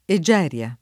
eJ$rLa] pers. f. mit.